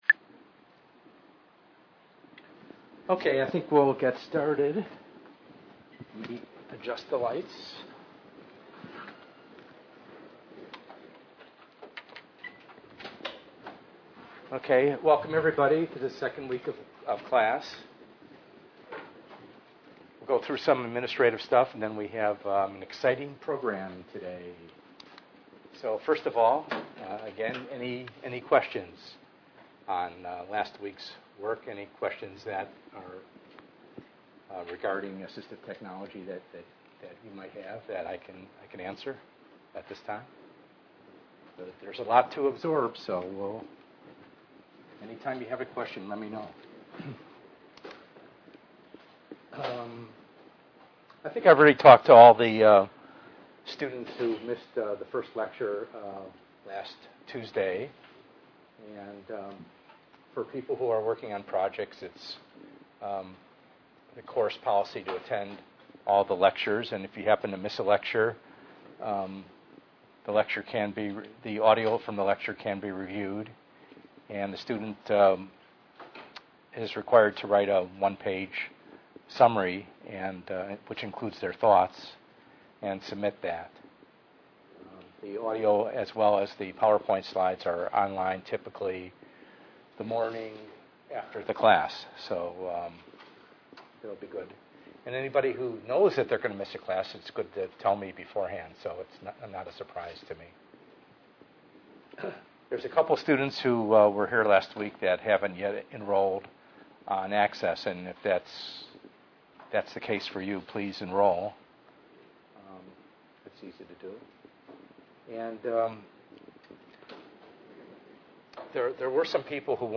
ENGR110/210: Perspectives in Assistive Technology - Lecture 2a